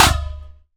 ShotImpactDeflect.wav